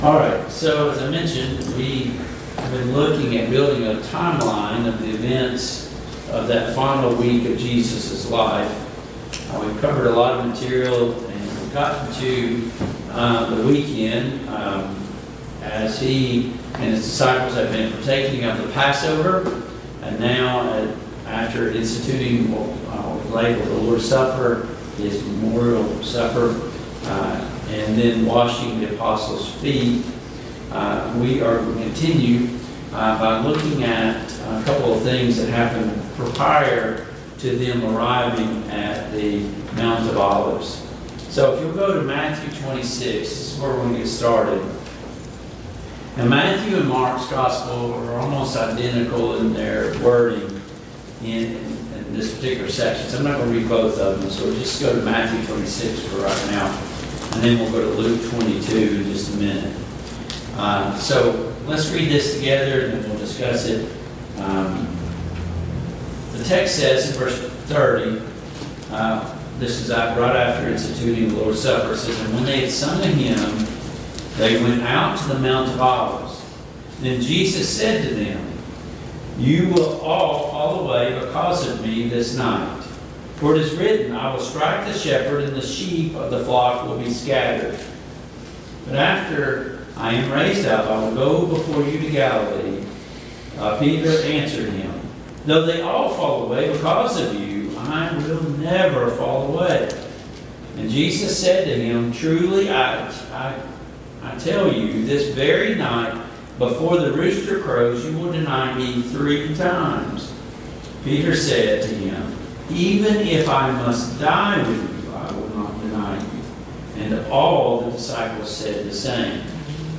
Mid-Week Bible Study Download Files Notes Topics: Jesus' Prayer in the Garden , Peter's Denial of Jesus « Pursue Purity 10.